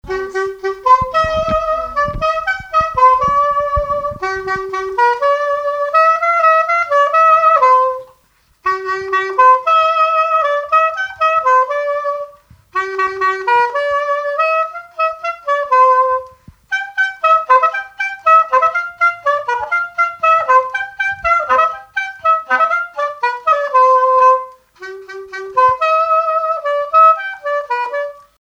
Mémoires et Patrimoines vivants - RaddO est une base de données d'archives iconographiques et sonores.
Chants brefs - A danser
Répertoire de musique traditionnelle
Pièce musicale inédite